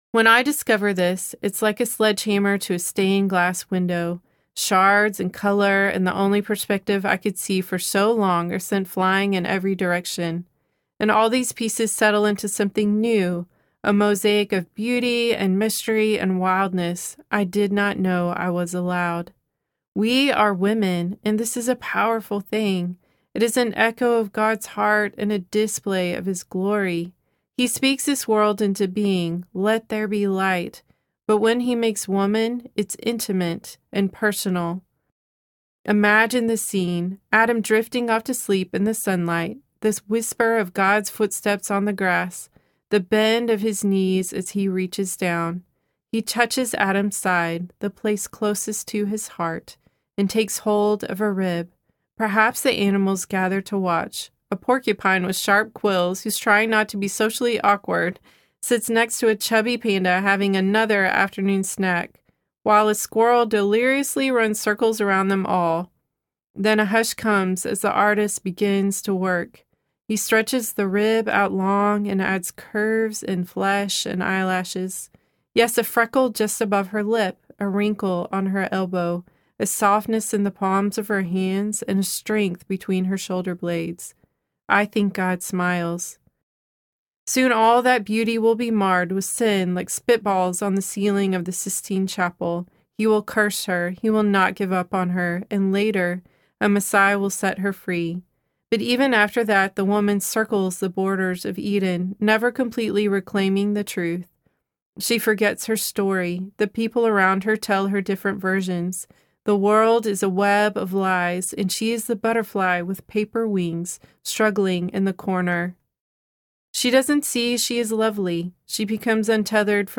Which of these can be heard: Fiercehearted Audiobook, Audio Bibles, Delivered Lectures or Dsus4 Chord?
Fiercehearted Audiobook